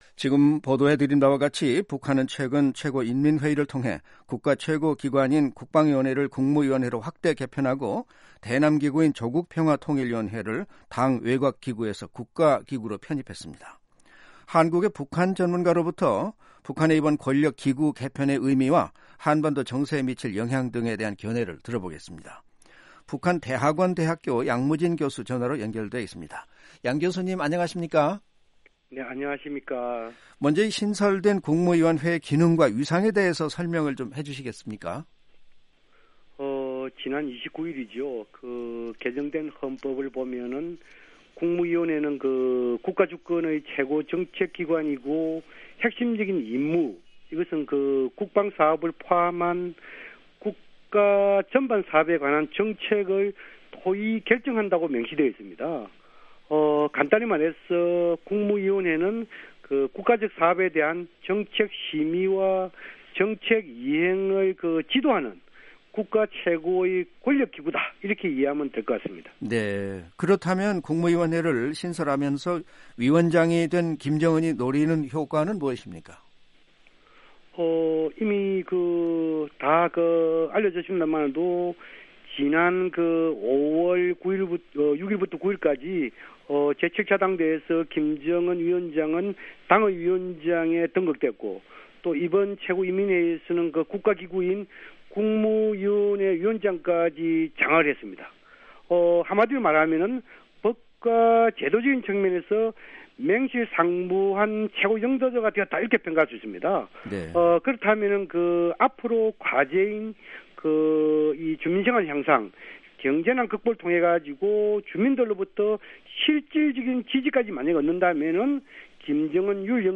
인터뷰